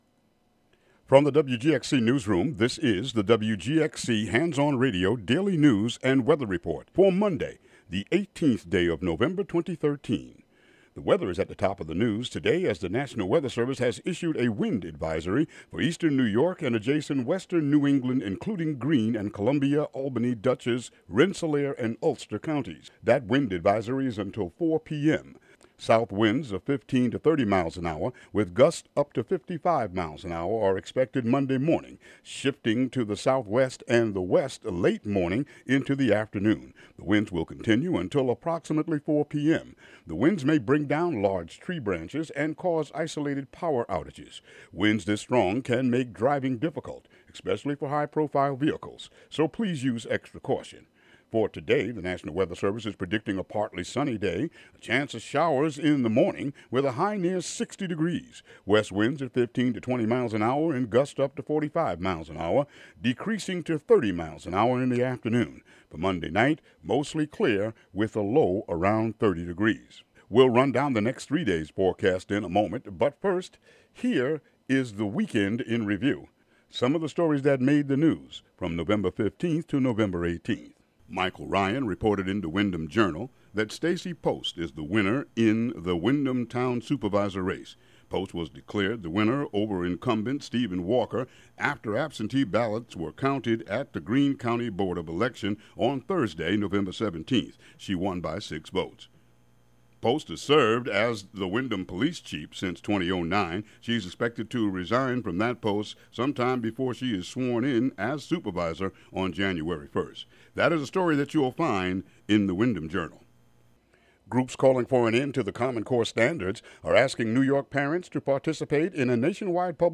Local news and weather for Monday, November 18, 2013.